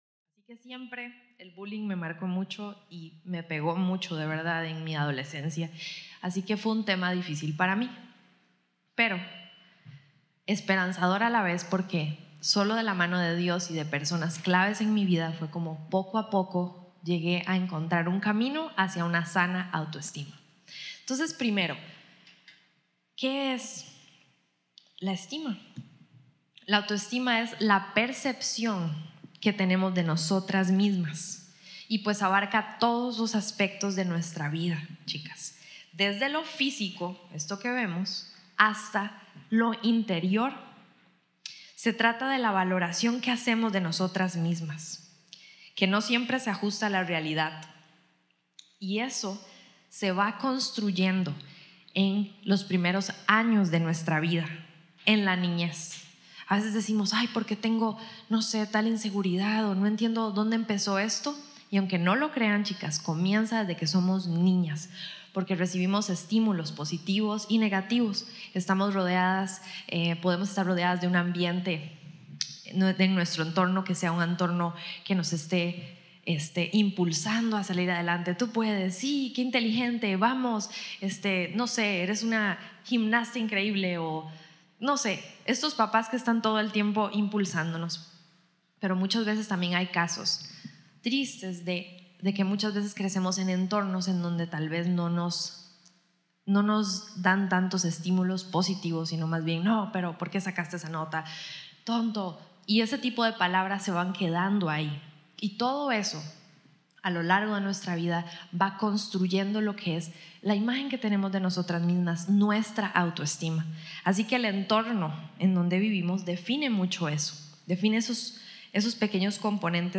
La Imágen Completa | Retiro de Jovencitas